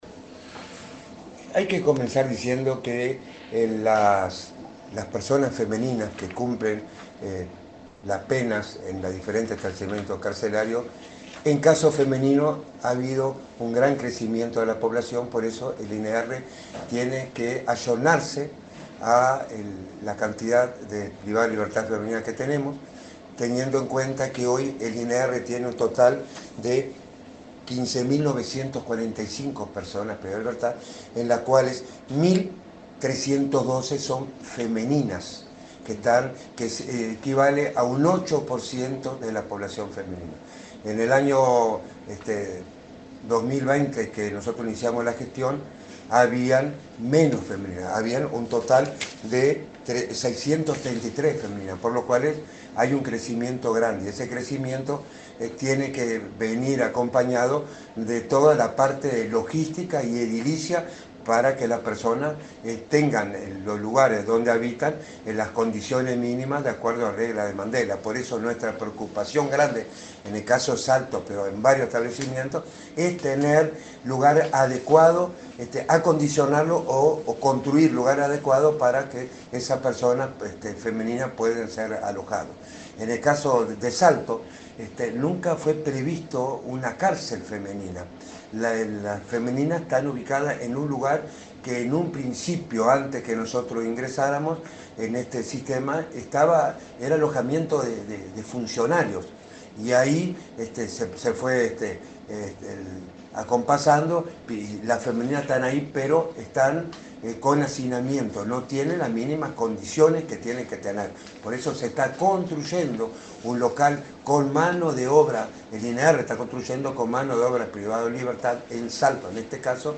Entrevista al director del INR, Luis Mendoza